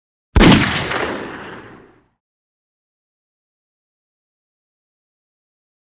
.357 Magnum Pistol Shot 1; Close Perspective.